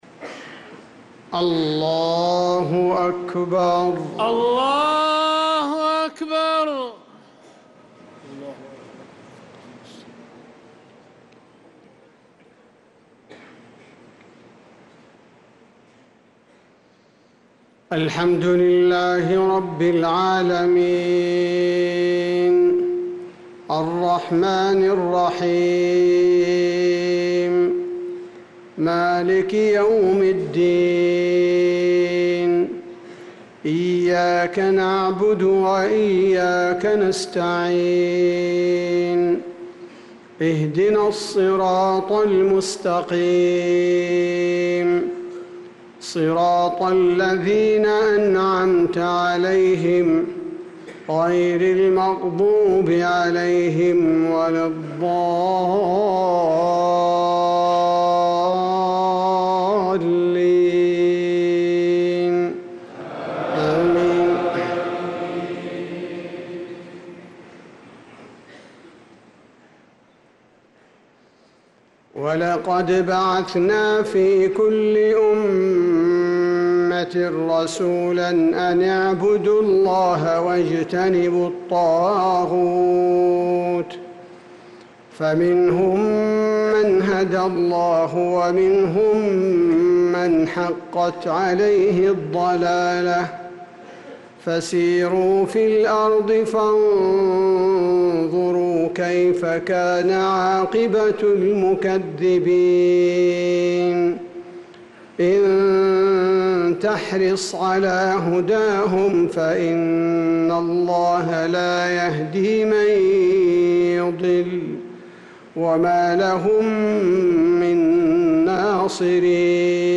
صلاة المغرب للقارئ عبدالباري الثبيتي 2 محرم 1446 هـ
تِلَاوَات الْحَرَمَيْن .